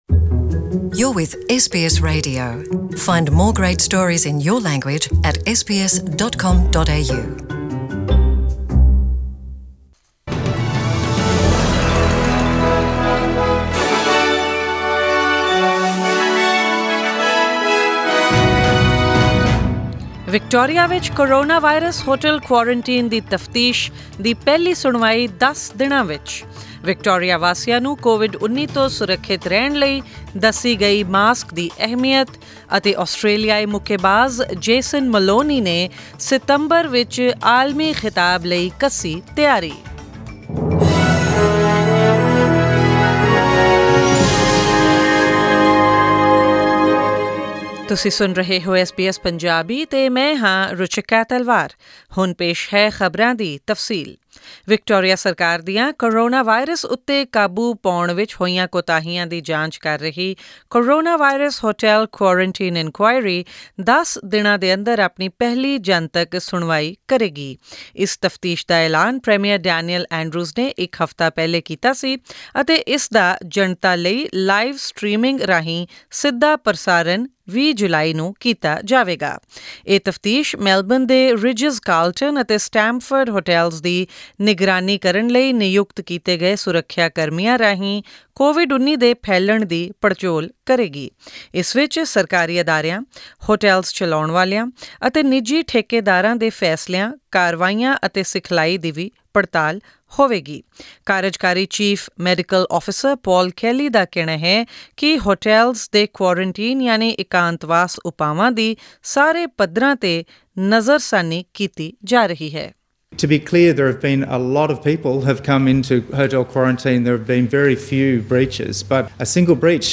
Australian News in Punjabi: 10 July 2020